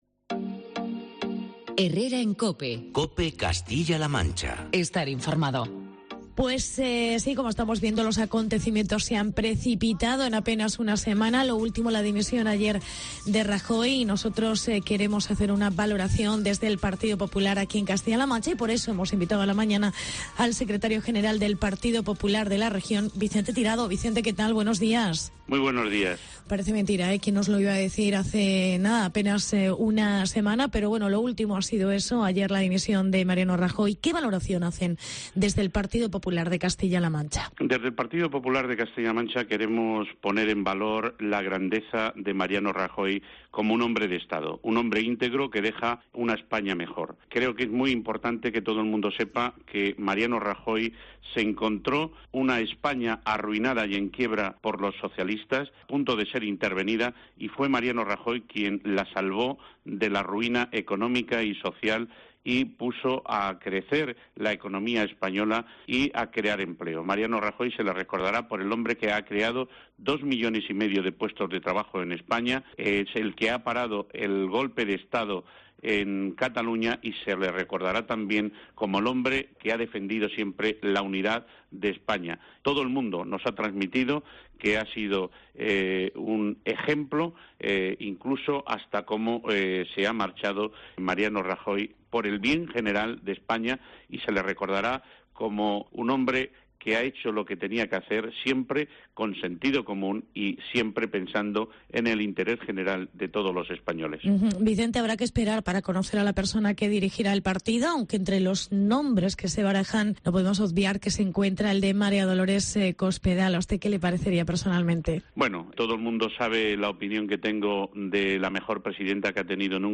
Entrevista con el secretario general: Vicente Tirado